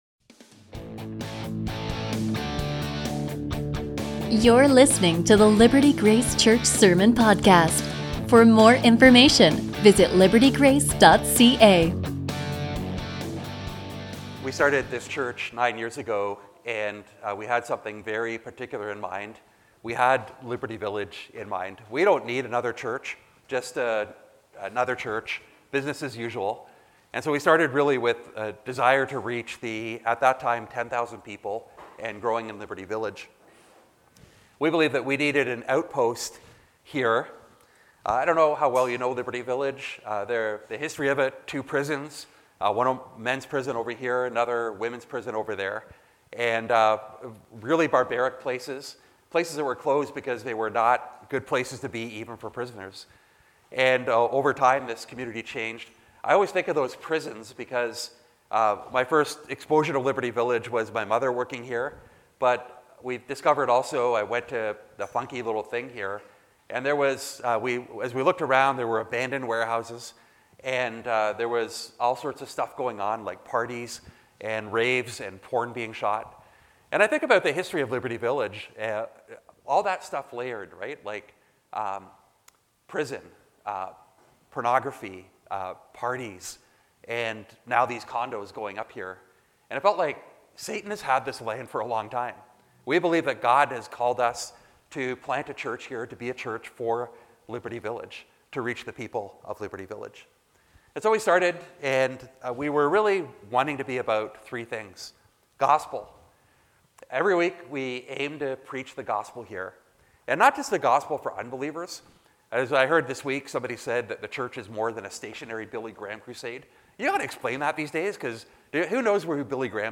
A sermon from Colossians 4:2-6